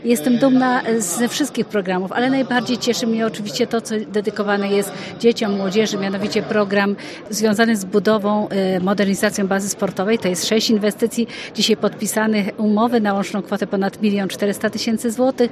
O jednym z nich mówi Elżbieta Lanc, członkini zarządu województwa mazowieckiego: